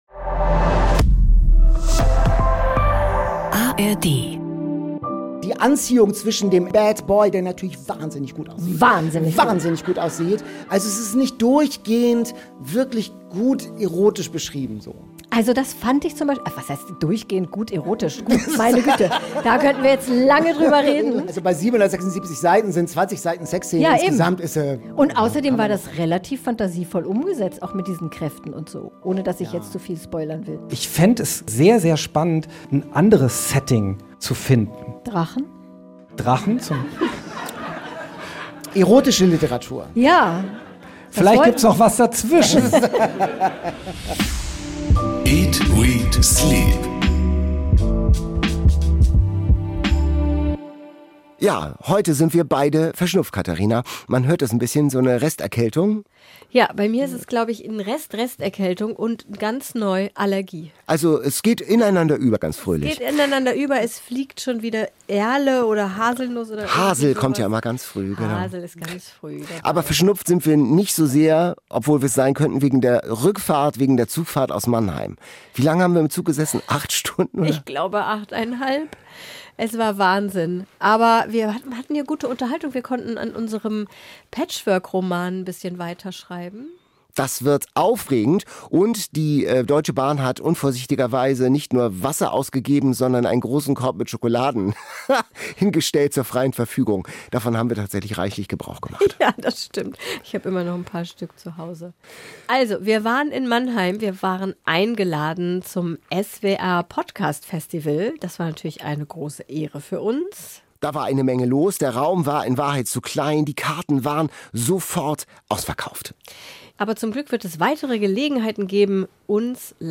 Zu Gast in dieser Folge, die beim SWR-Podcastfestival in Mannheim aufgenommen wurde, ist Tobi Schlegl, der nicht nur Autor und Moderator ist, sondern auch als Rettungssanitäter arbeitet und diese Erfahrungen auch in seinen Romanen verarbeitet.